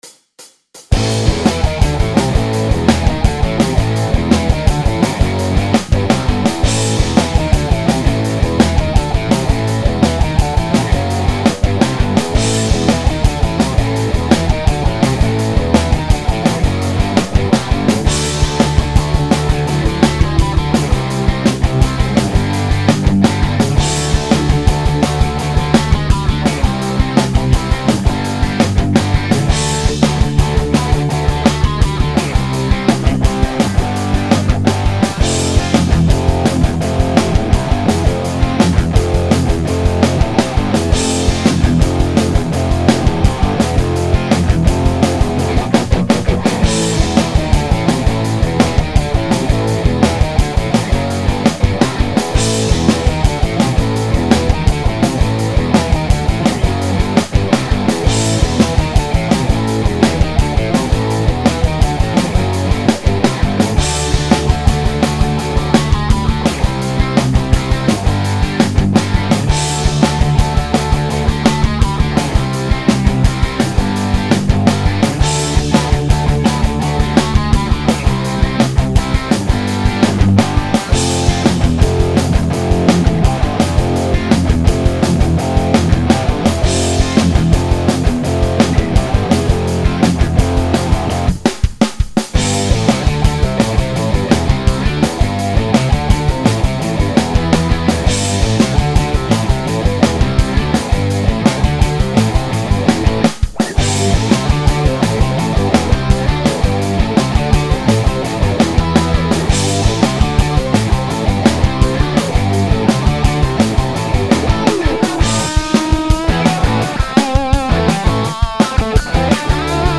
I composed all of these pieces and played all guitars. Drums, keyboards, and some bass guitars I programmed via computer and some bass guitars are my playing an actual bass guitar.